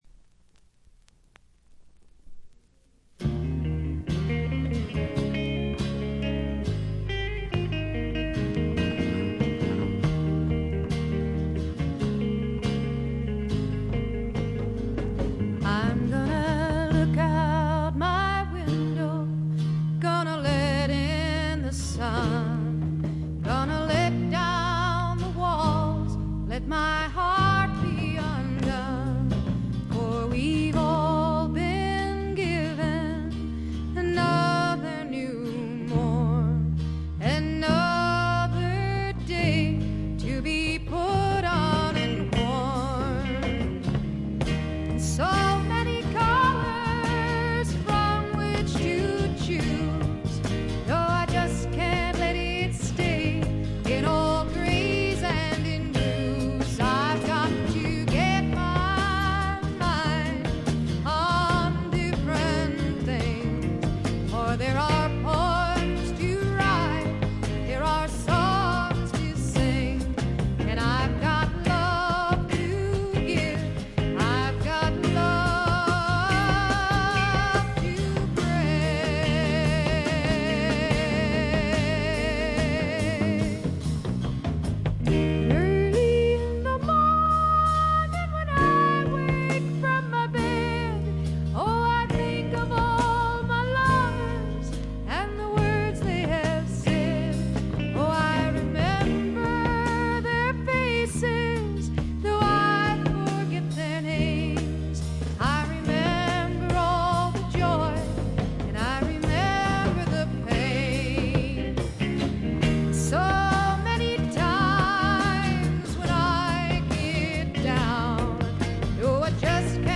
ところどころでチリプチ。大きなノイズはありません。
試聴曲は現品からの取り込み音源です。